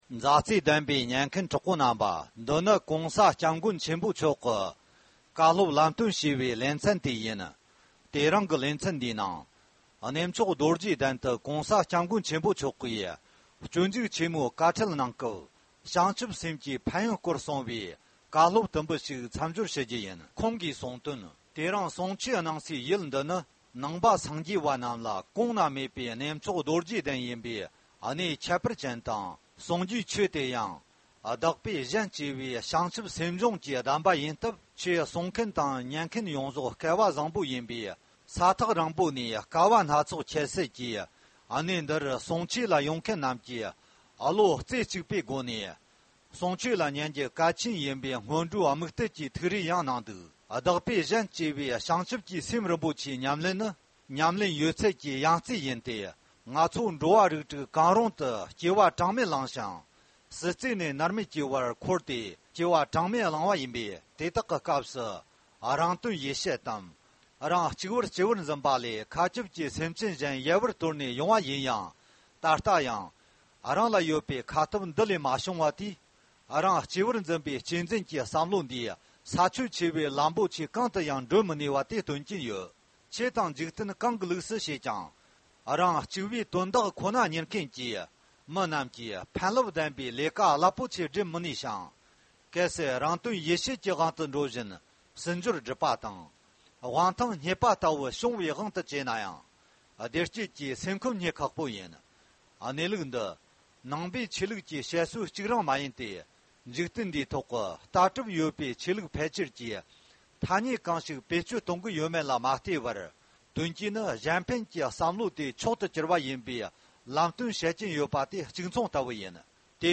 ༸གོང་ས་༸སྐྱབས་མགོན་ཆེན་པོ་མཆོག་གིས་གནས་མཆོག་རྡོ་རྗེ་གདན་དུ་སྤྱོད་འཇུག་བཀའ་ཁྲིད་གནང་སྐབས་བྱང་ཆུབ་སེམས་ཀྱི་ཕན་ཡོན་སྐོར་བཀའ་སློབ་གནང་བ།